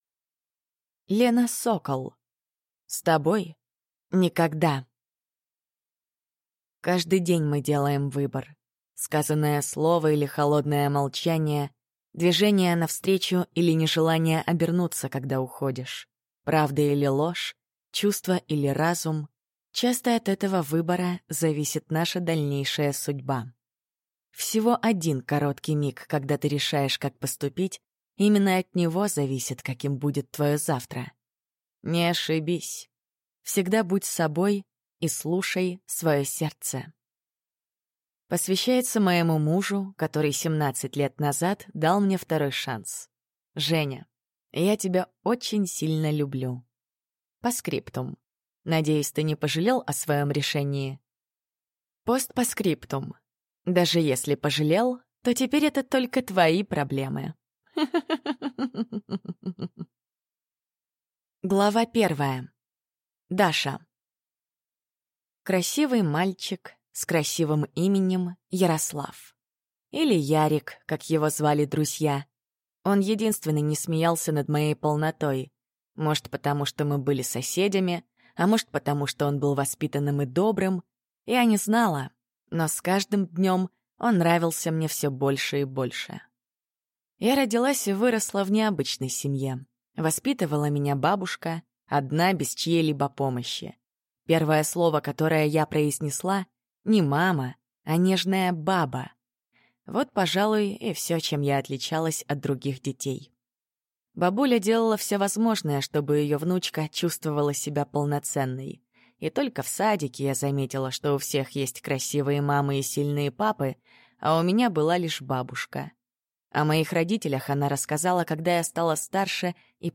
Аудиокнига С тобой? Никогда!